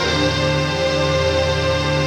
RAVEPAD 09-LR.wav